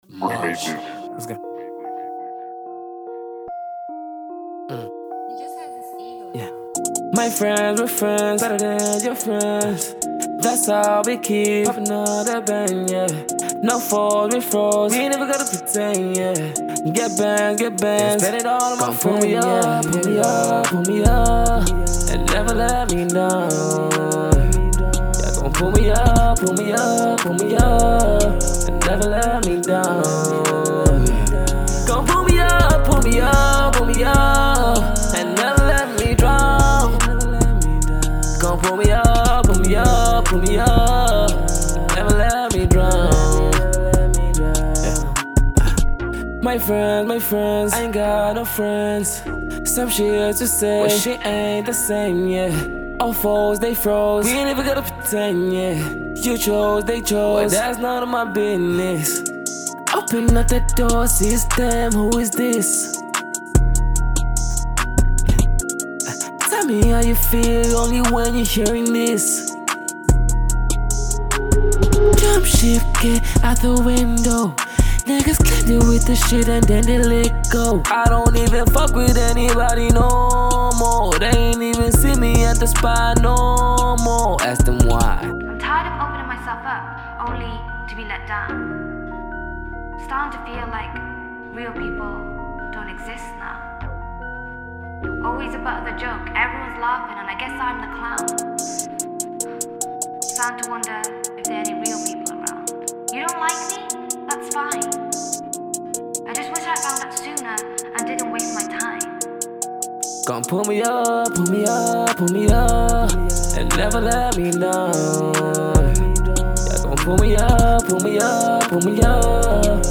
Cover
rapper